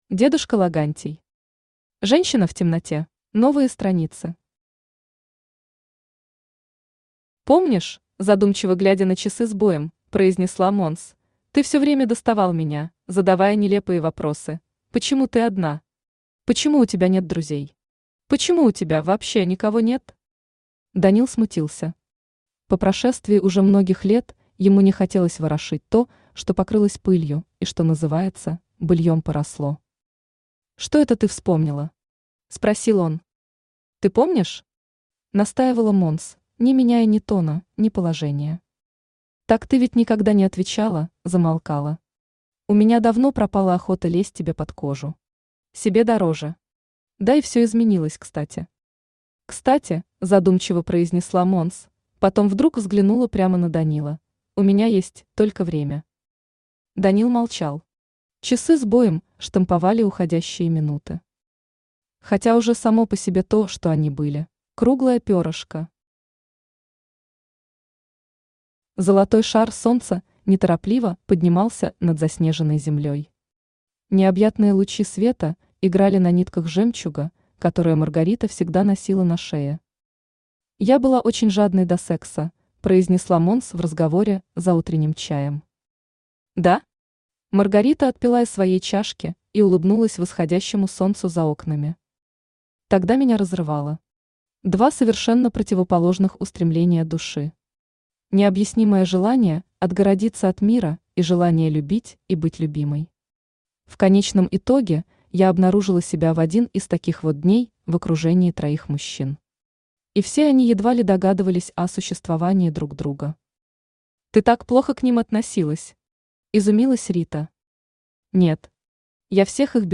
Аудиокнига Женщина в темноте | Библиотека аудиокниг
Aудиокнига Женщина в темноте Автор дедушка Логантий Читает аудиокнигу Авточтец ЛитРес.